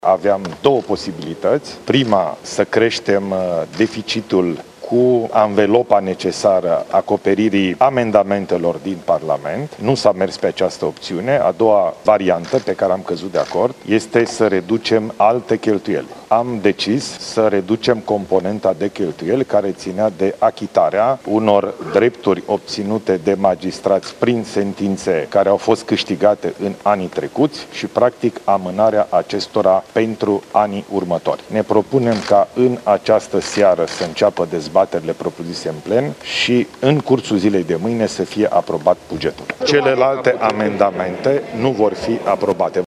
Premierul Ilie Bolojan: „Aveam două posibilităţi”